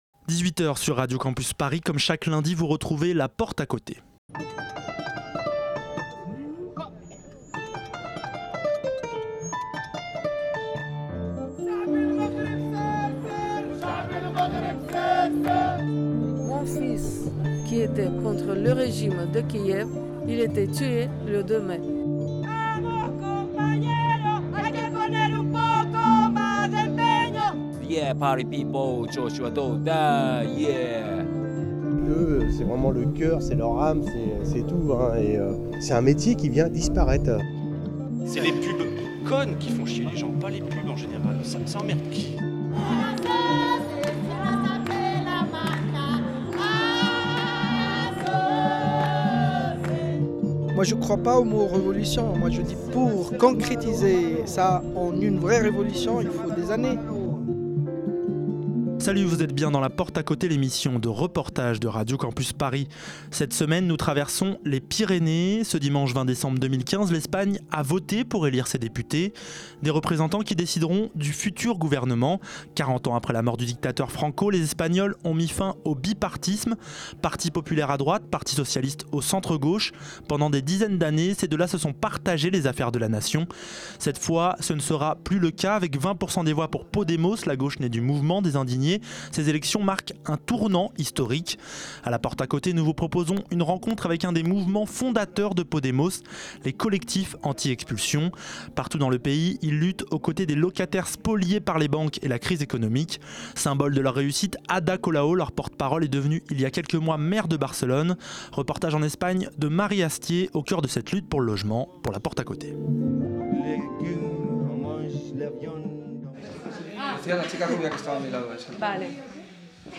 Reportage au cœur de cette lutte pour le logement.